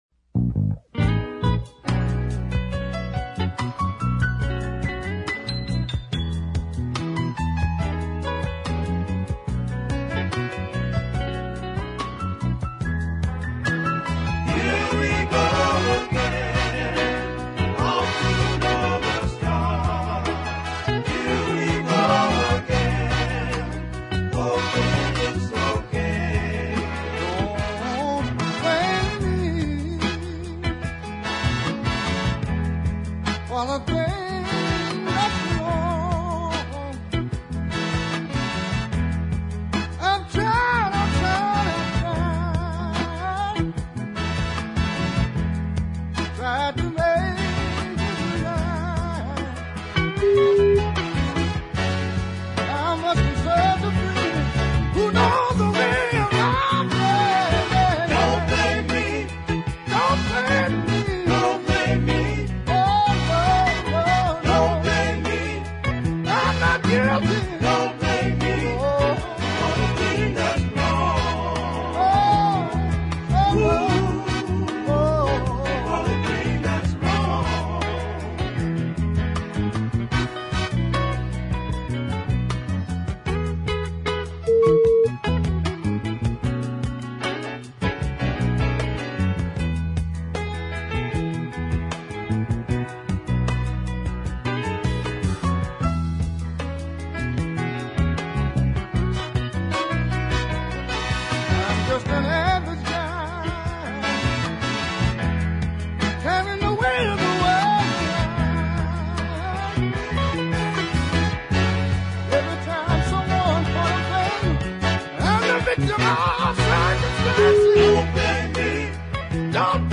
tenor sax
gruff, rough vocals